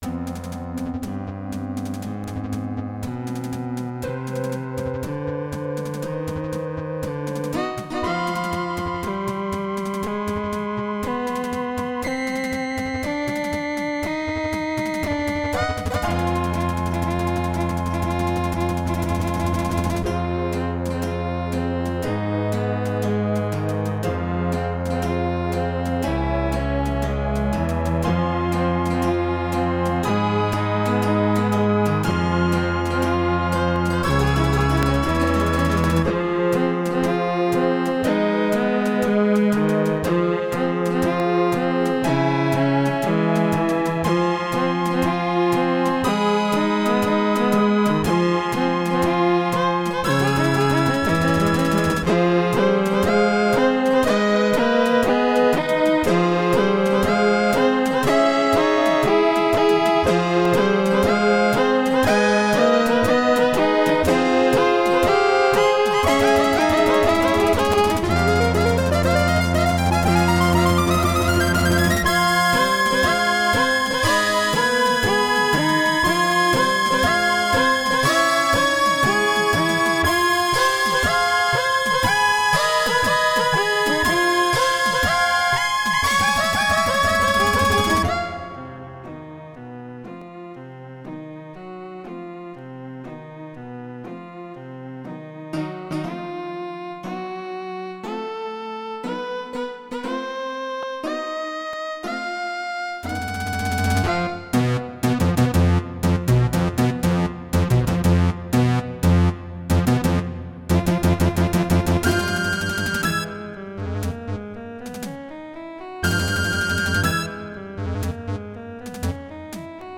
Roland LAPC-I
* Some records contain clicks.